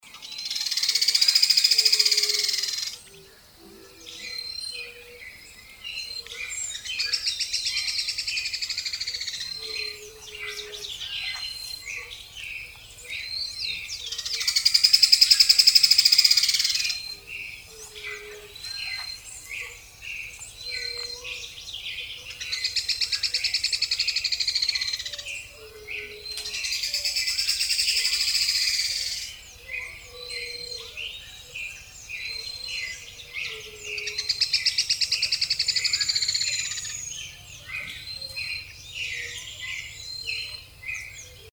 Syndactyla rufosuperciliata acrita
English Name: Buff-browed Foliage-gleaner
Life Stage: Adult
Detailed location: Reserva privada Ivytu
Condition: Wild
Certainty: Recorded vocal